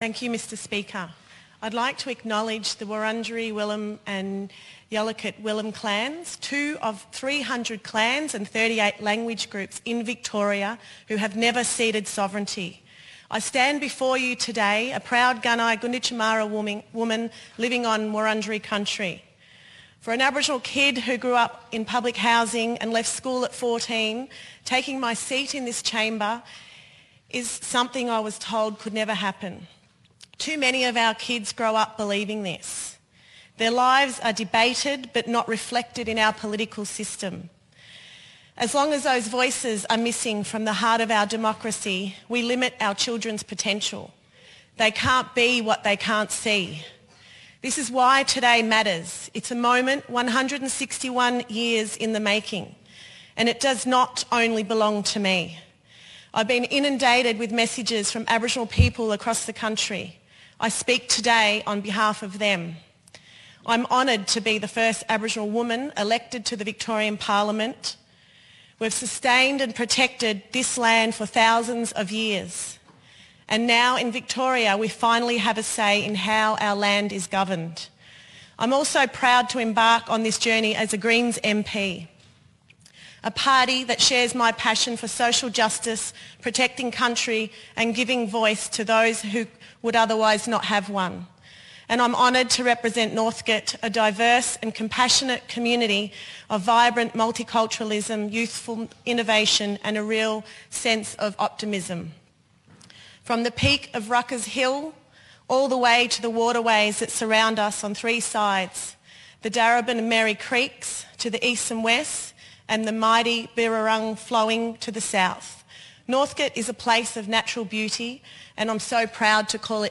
In her maiden speech, the newly elected greens MP and first Aboriginal woman in Victoria’s parliament stressed that as an Aboriginal child who grew up in public housing she was led to believe that she would never become an MP.